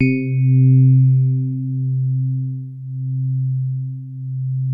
FINE SOFT C2.wav